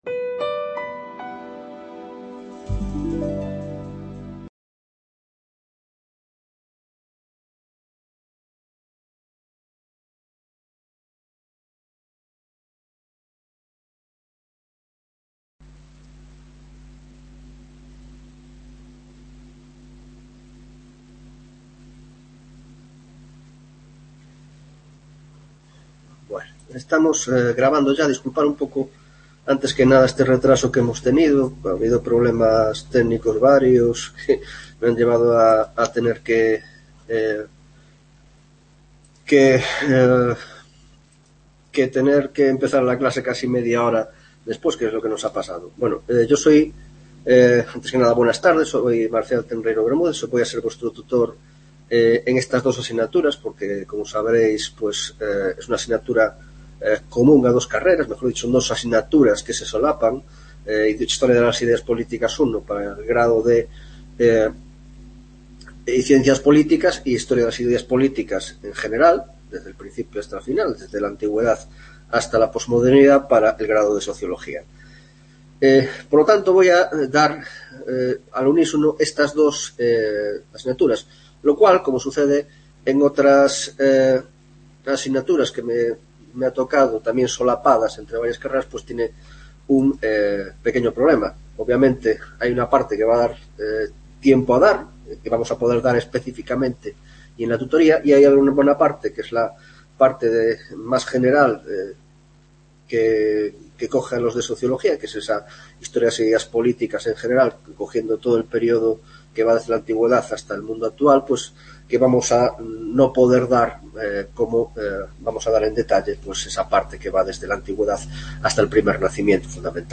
1ª Tutoria de Historia de las Ideas Políticas